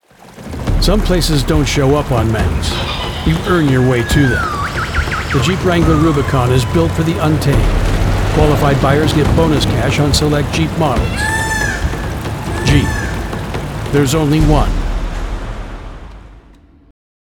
Gritty, Rugged, American Voiceover
• Gritty, masculine tone with Western authenticity
• Cinematic pacing perfect for national TV and radio
Truck Commercial Voiceover Demos
• Fully treated booth